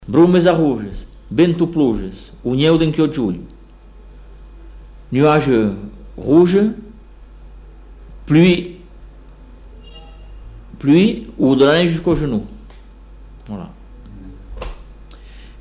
Aire culturelle : Comminges
Lieu : Bagnères-de-Luchon
Genre : forme brève
Effectif : 1
Type de voix : voix d'homme
Production du son : récité
Classification : proverbe-dicton